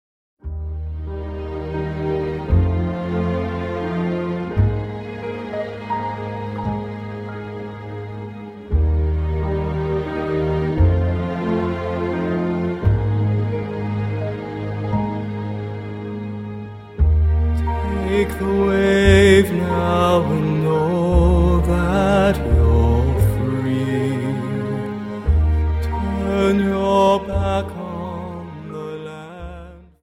Dance: Slow Waltz 29 Song